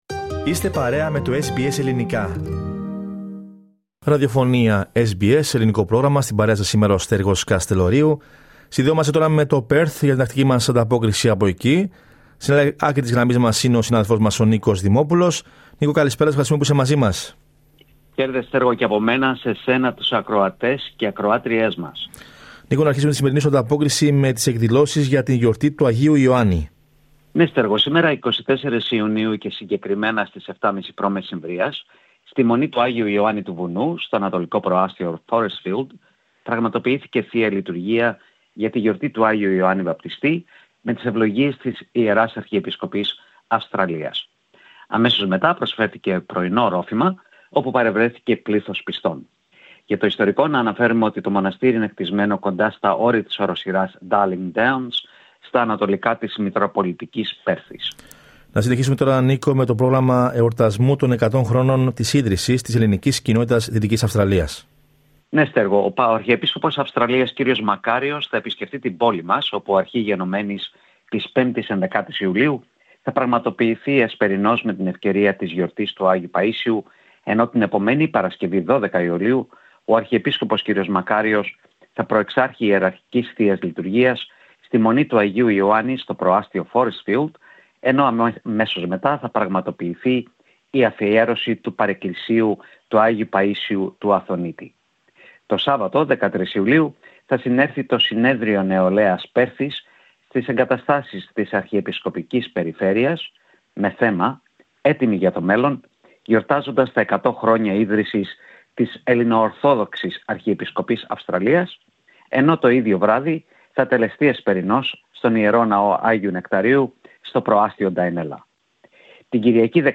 ανταπόκριση